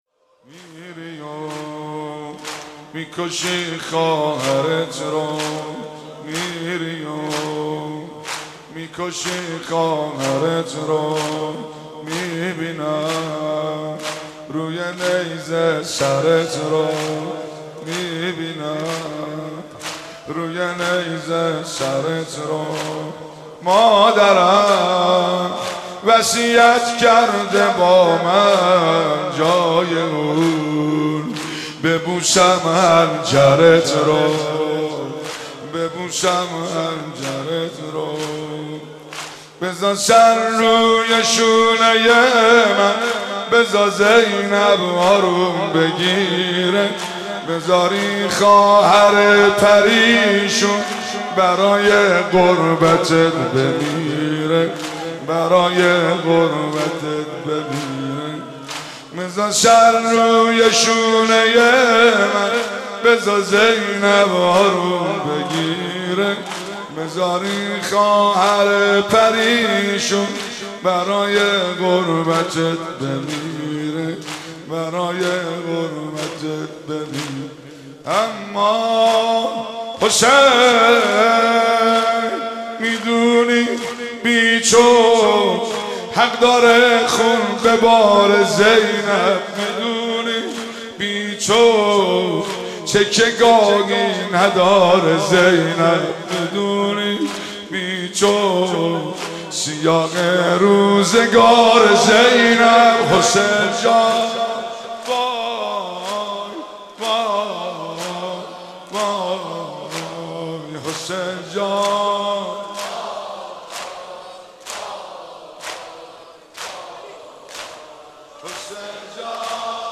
محرم 94